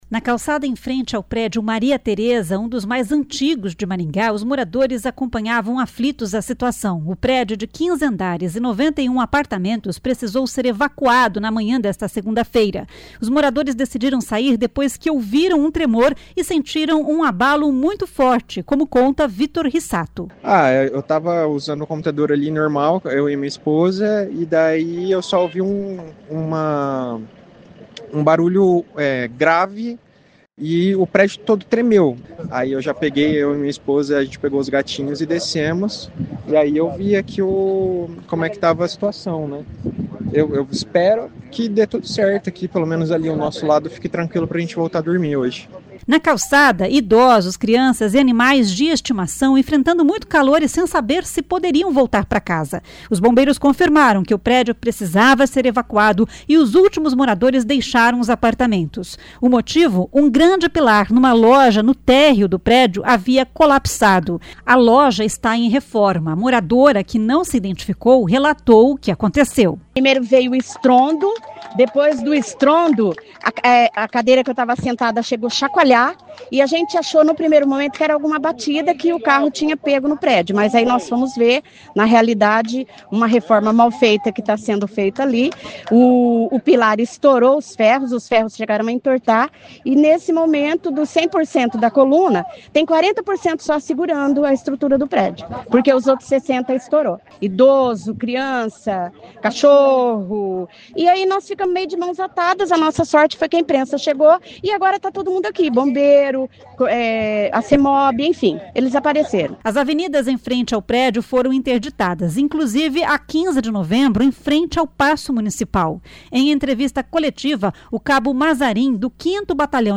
A moradora que não se identificou relatou o que aconteceu.